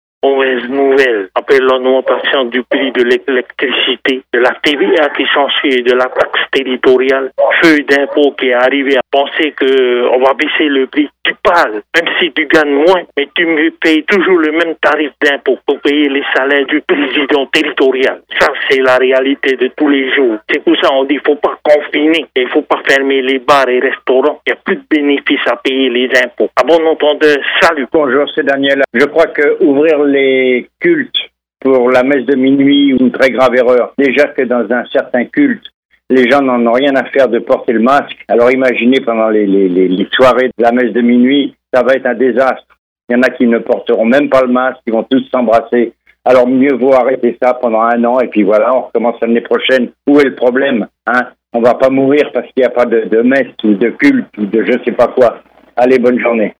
Répondeur de 6:30, le 11/12/20